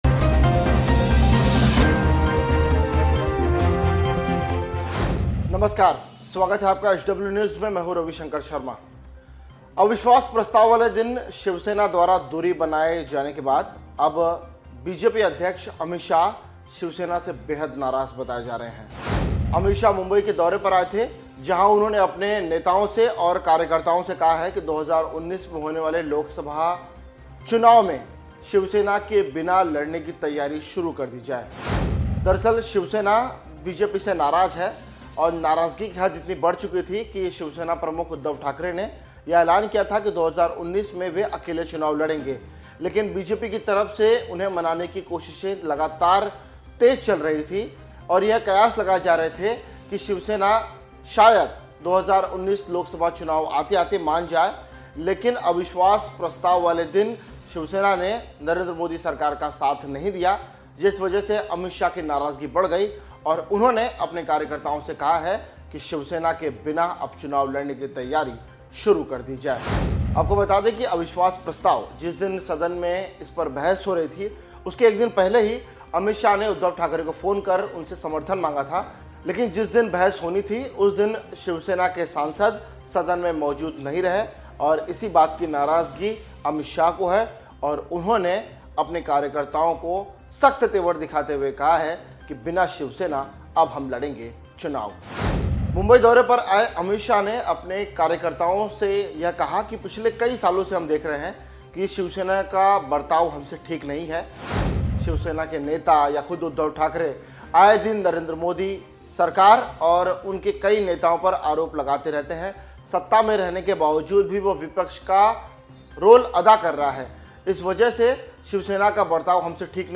न्यूज़ रिपोर्ट - News Report Hindi / शिवसेना से नाराज नरेंद्र मोदी, उद्धव ठाकरे को चुनाव में मात देने के लिए बनाया यह फार्मूला